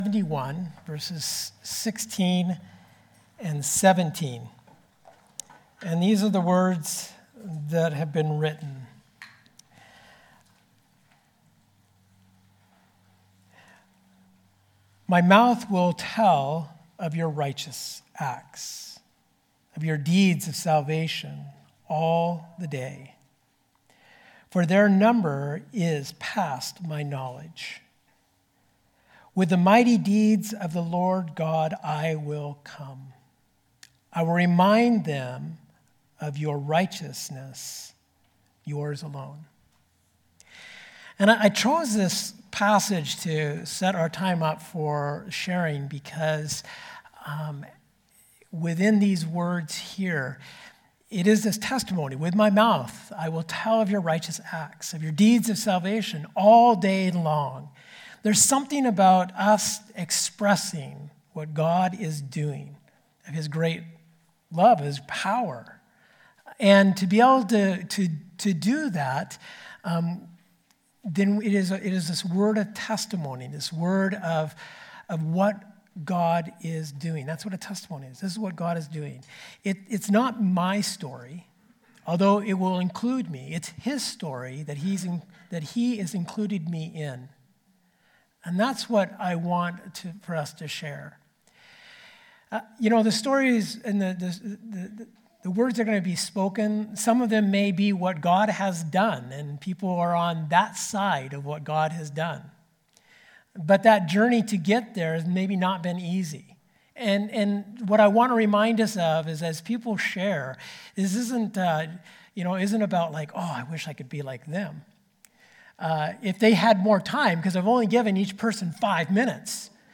This past Sunday, several members of the OCC family shared a testimony of what God is doing in their lives.